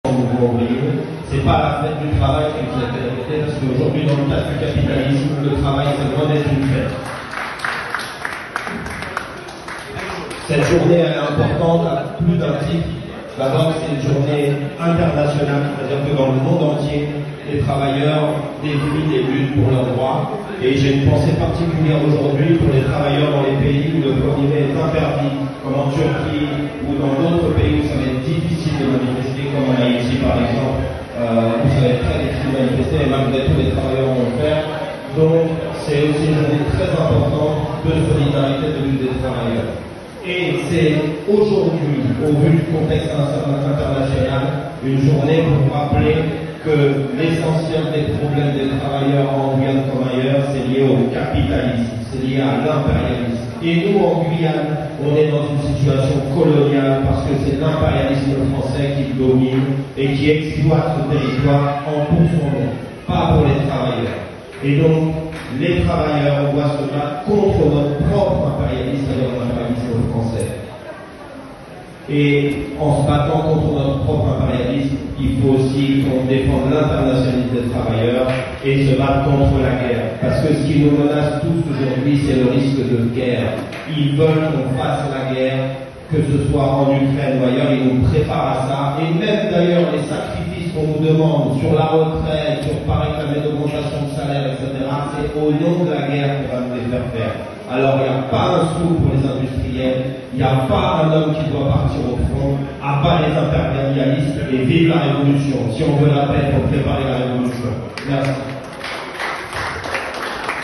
1er mai 2025 à Cayenne (Guyane) : Intervention de
Intervention